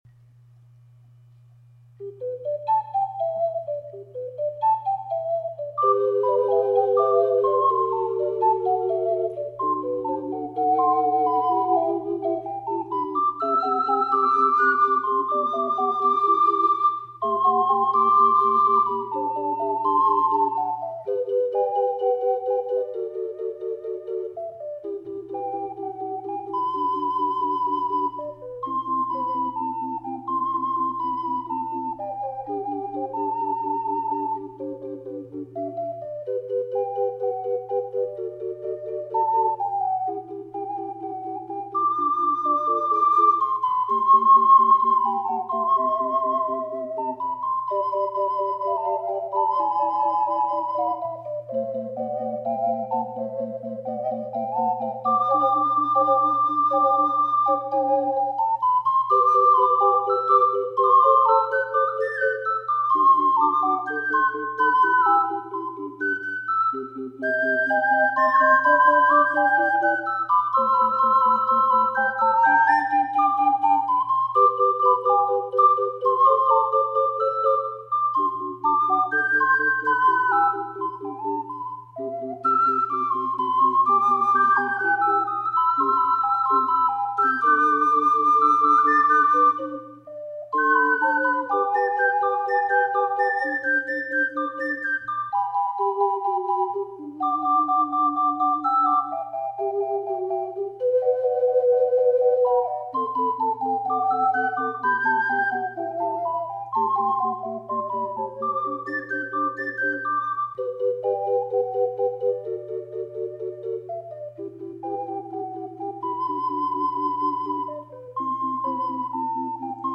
試奏では(2)のタイプのAC管を使いました。